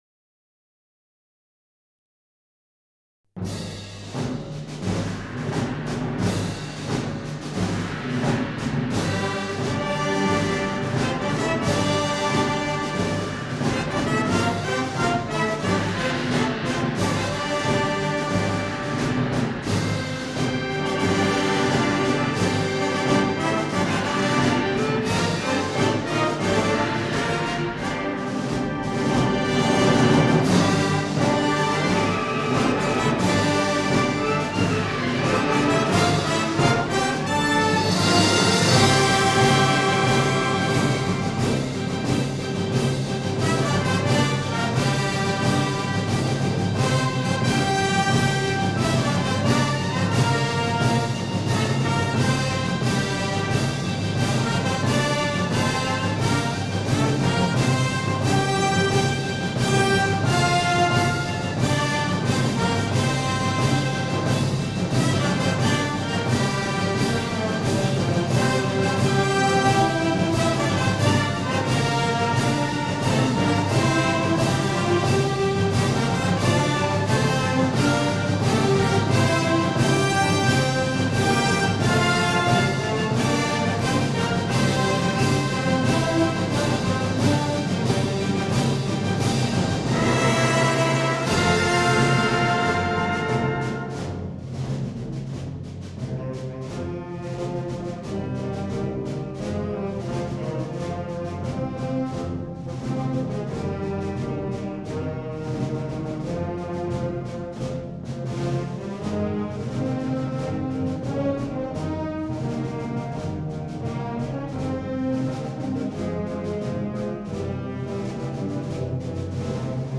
Marcha cristiana